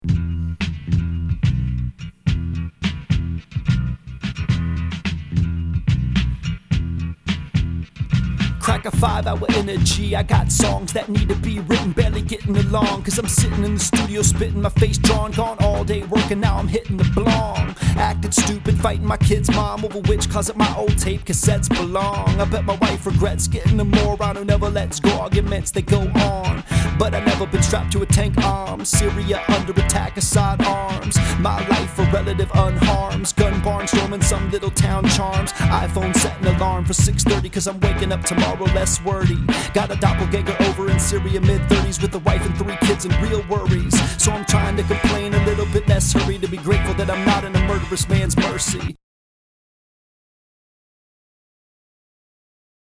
Oh, and my recording just woke my daughter (1:48 am).